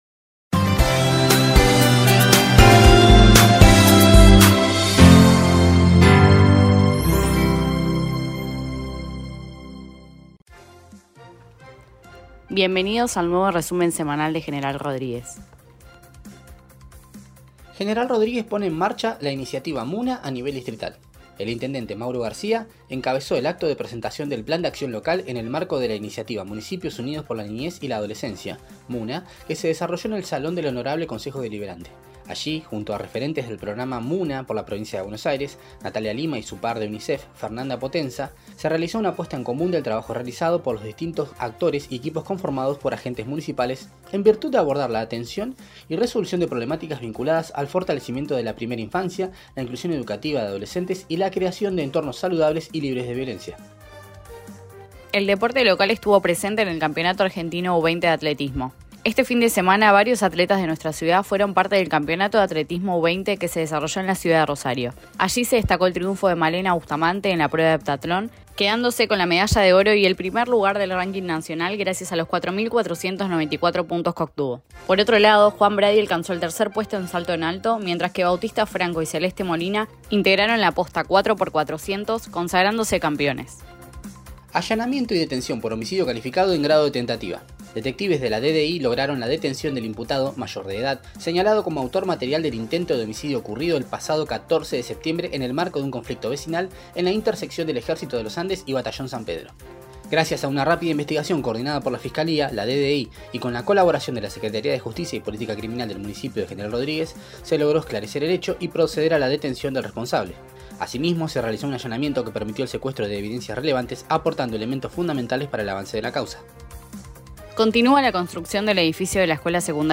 te acercamos este resumen de noticias semanales de General Rodríguez.